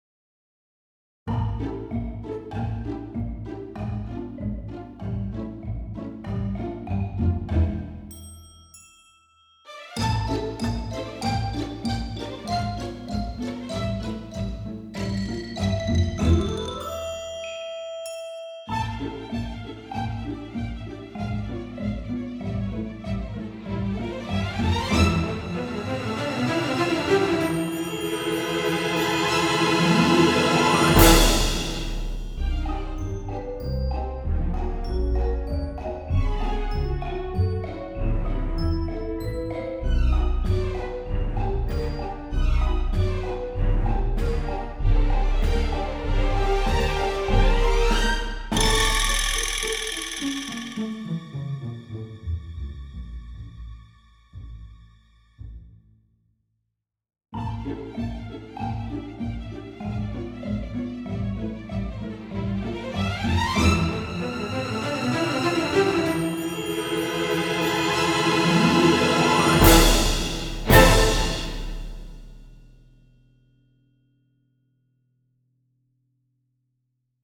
featuring runs